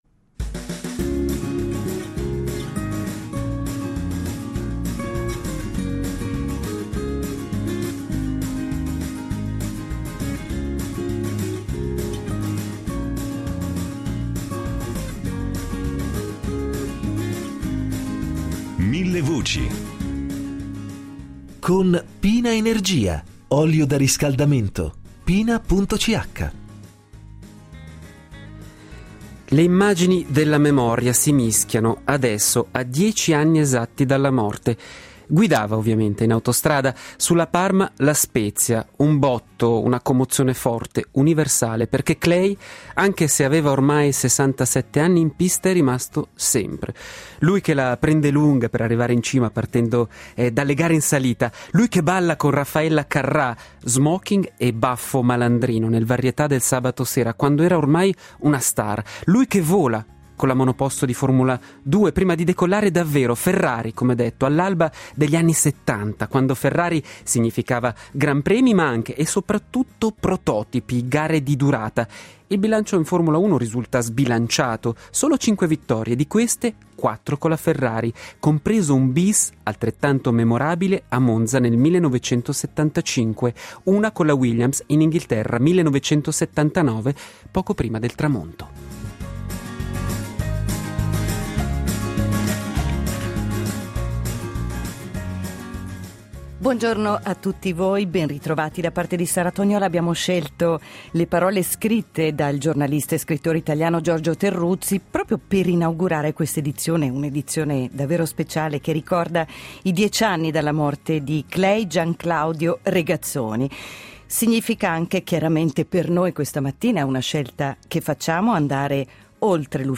un ritratto sonoro dell'indimenticato campione a 10 anni dalla scomparsa.